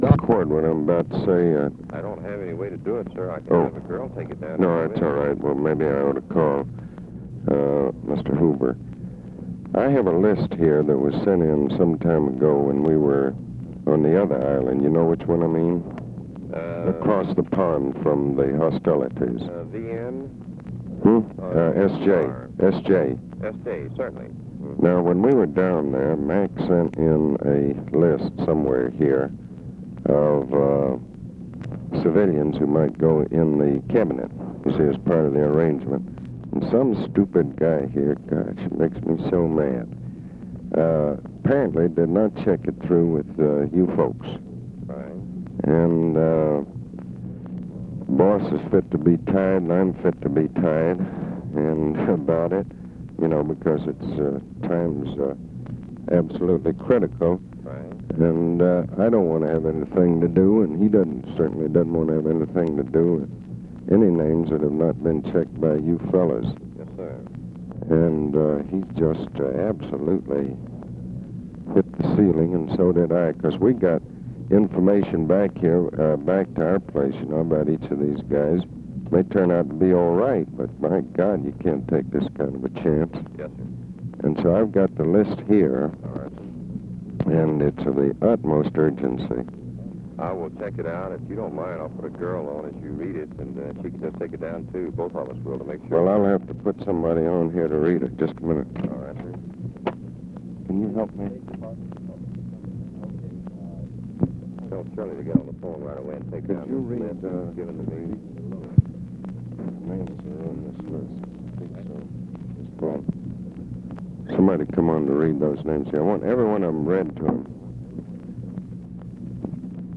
Conversation with CARTHA DELOACH and ABE FORTAS
Secret White House Tapes